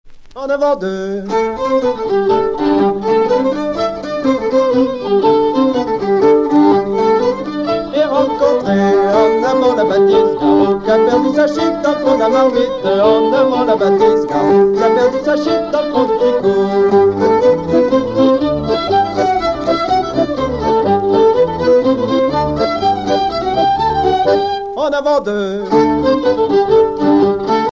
Couplets à danser
danse : quadrille : avant-deux
Pièce musicale éditée